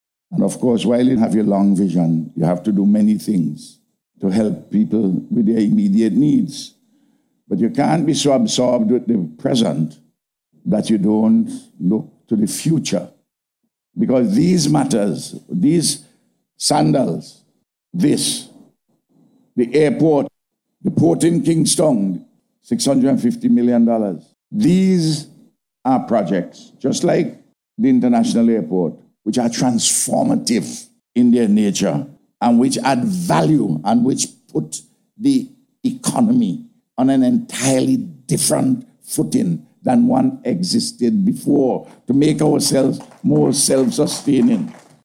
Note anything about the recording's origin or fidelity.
He made this statement during the Handing Over Ceremony of the Hotel and Suites on Saturday afternoon.